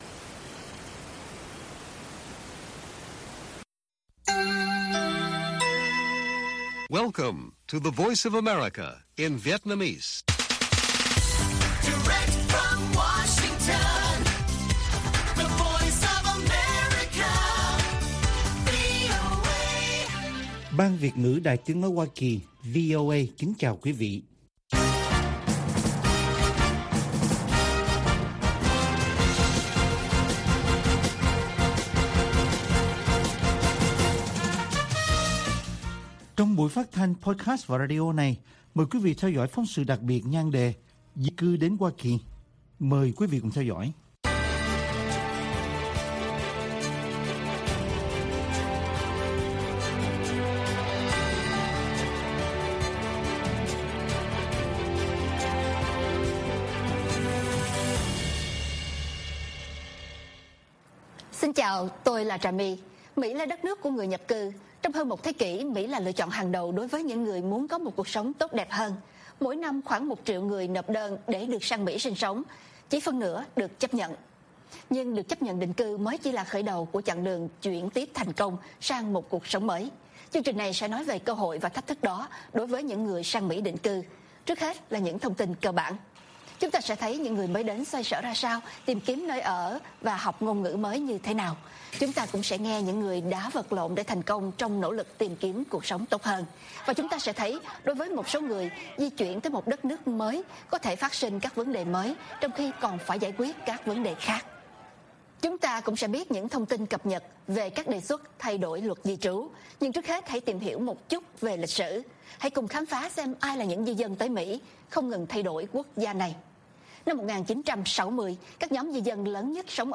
Bản tin VOA ngày 13/11/2021